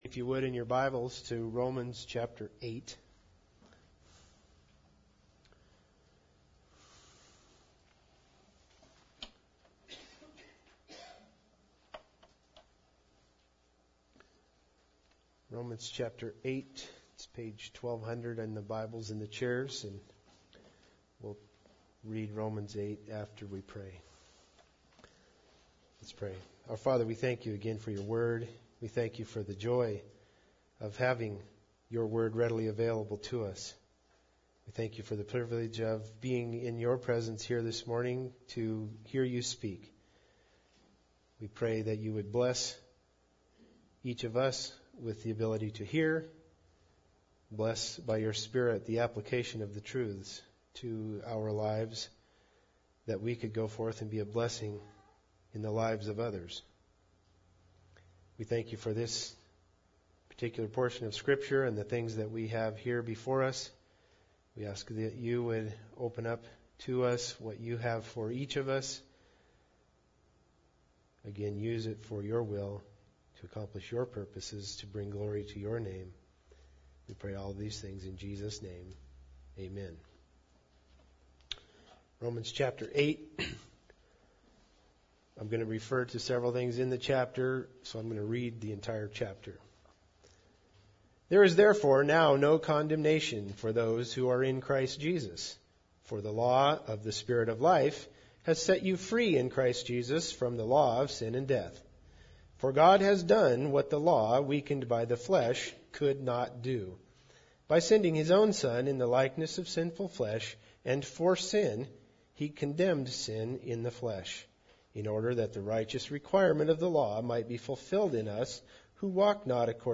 Romans 8:11 Service Type: Sunday Service Bible Text